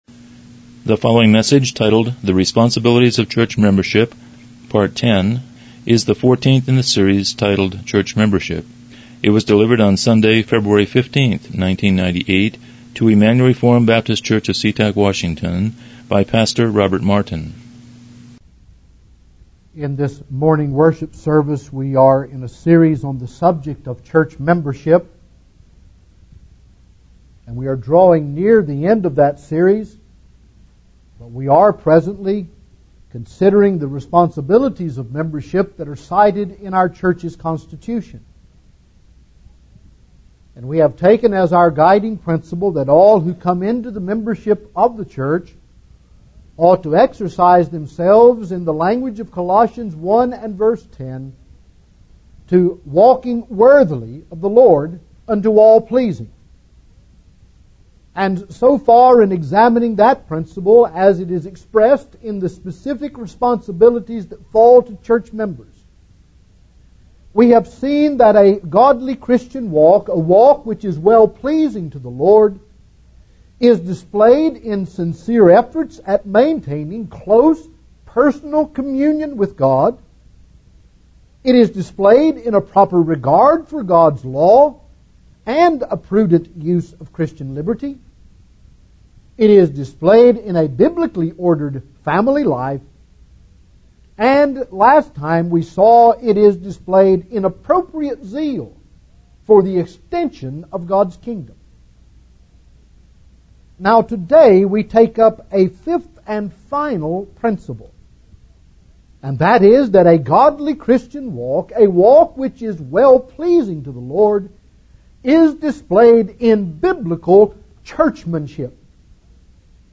Church Membership Service Type: Morning Worship « 13 Responsibilities of